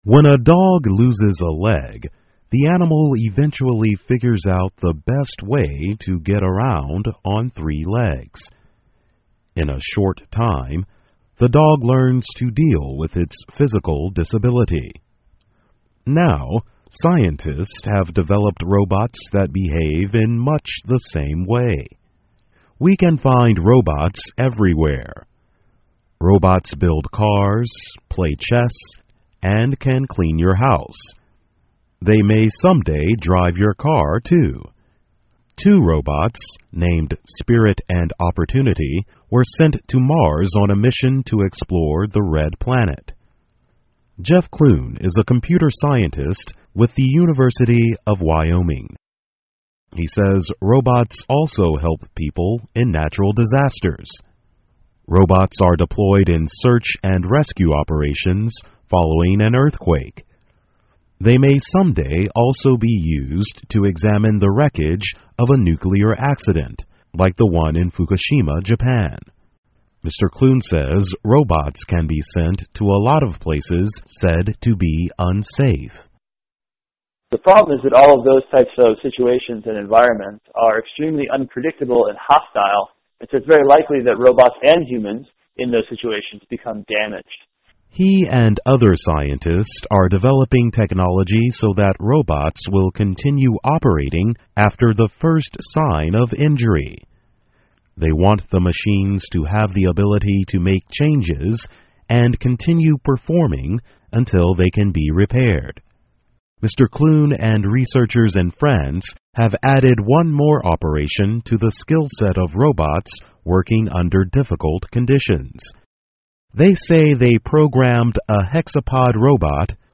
VOA慢速英语(翻译+字幕+讲解):能够自我修复的机器人